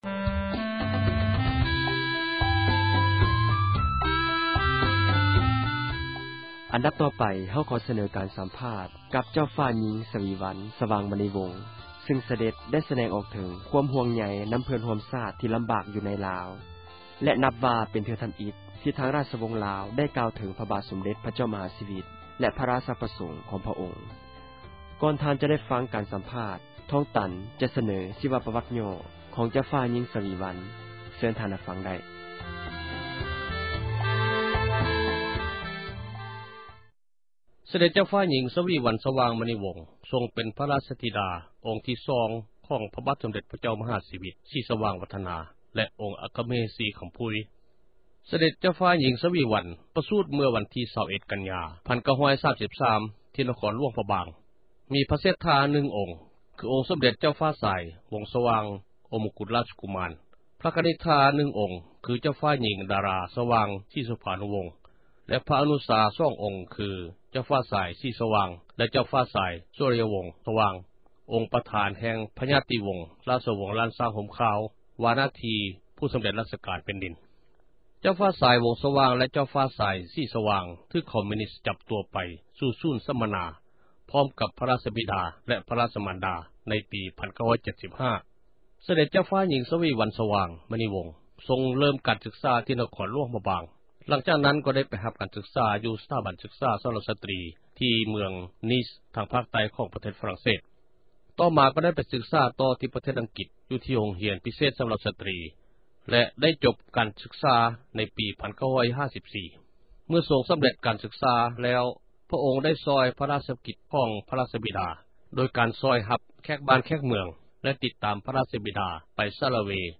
ບົດສຳພາດ ເຈົ້າຍິງ ສະວີວັນ